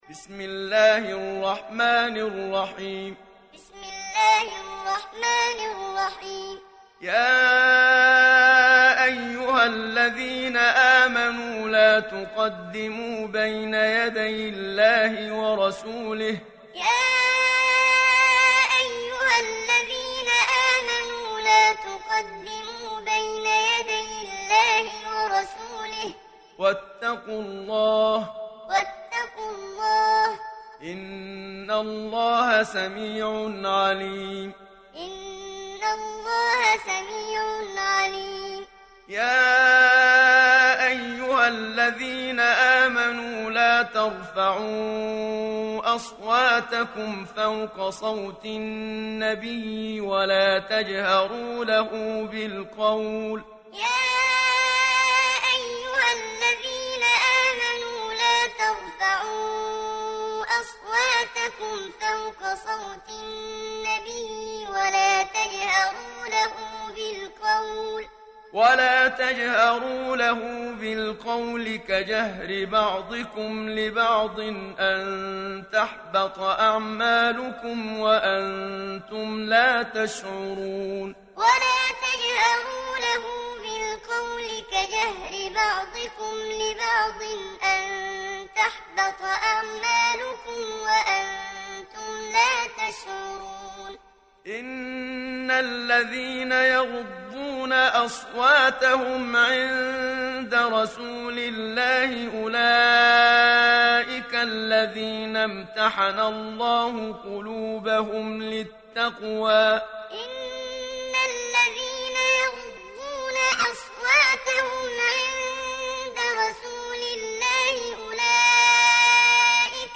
Hafs an Asim
Muallim